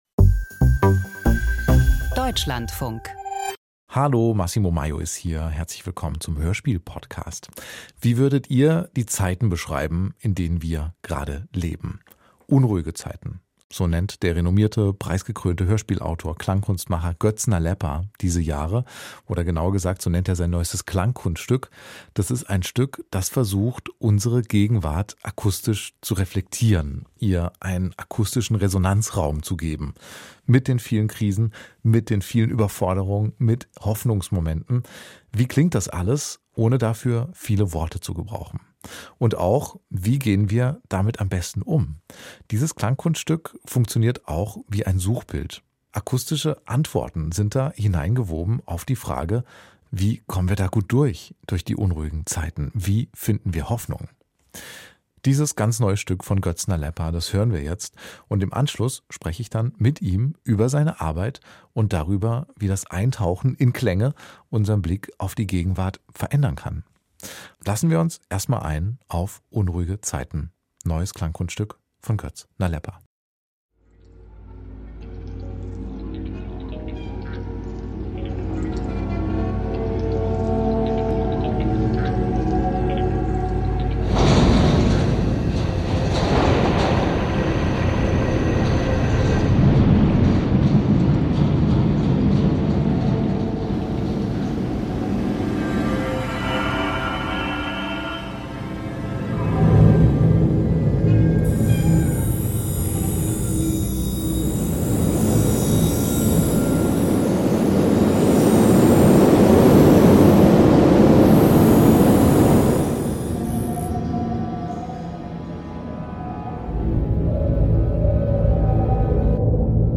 Sound Art
in einer düsteren Komposition